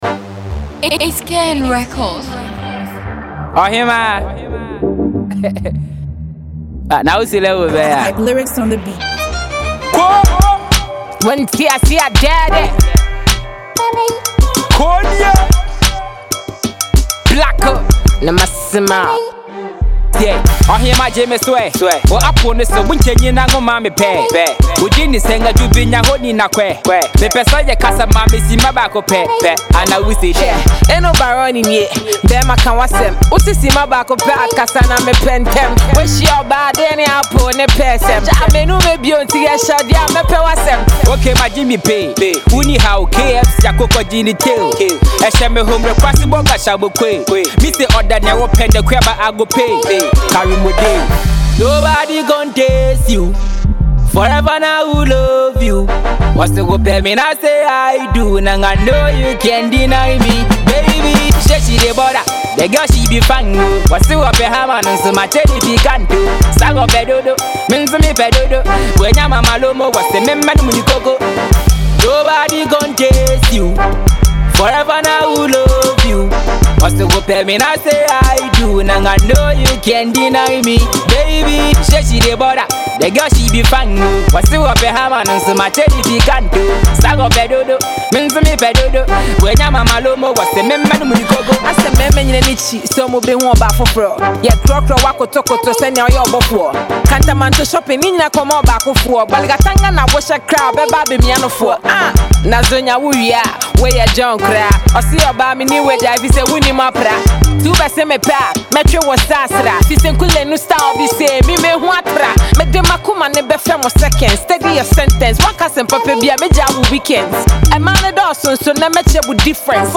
female rapper
hip hop